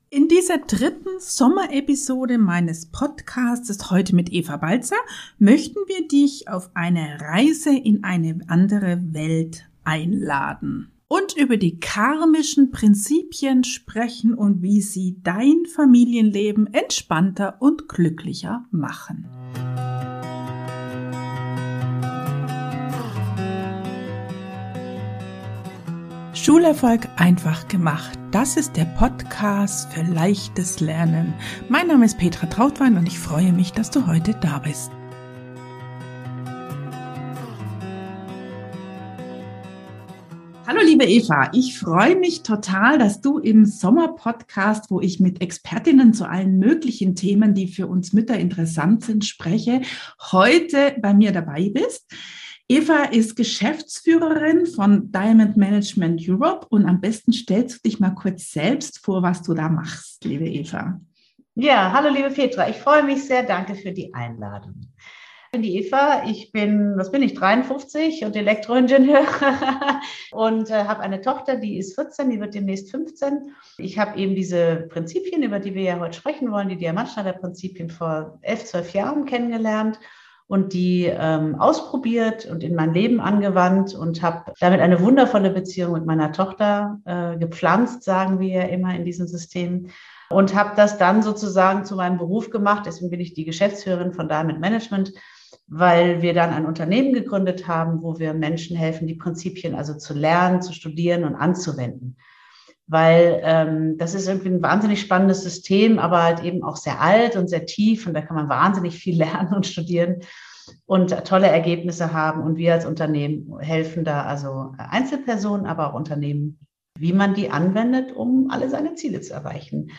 Sommer-Interview